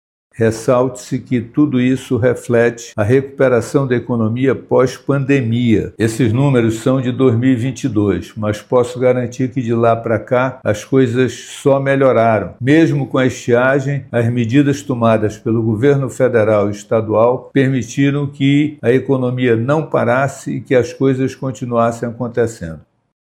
O titular da Sedecti, Serafim Corrêa, explica o motivo desta defasagem nos números e relata a expectativa para que o Amazonas mantenha números positivos em 2023 e 2024.